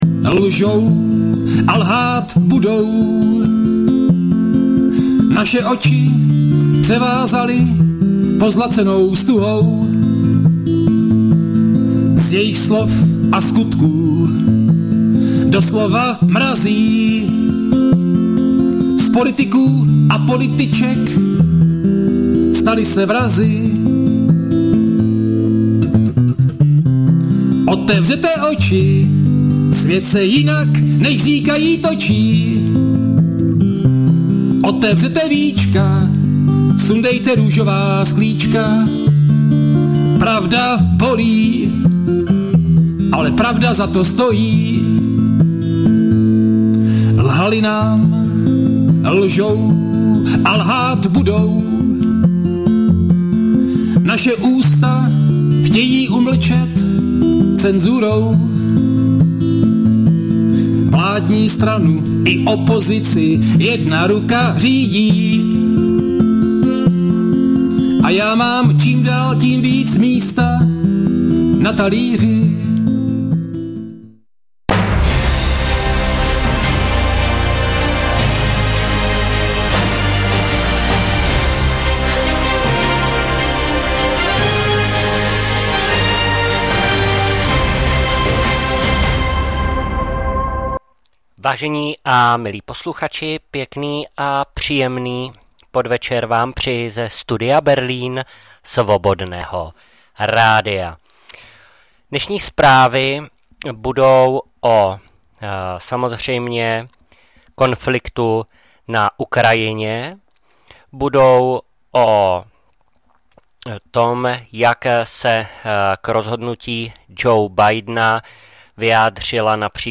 2024-11-20 – Studio Berlín – Zpravodajství – Zprávy z domova i ze zahraničí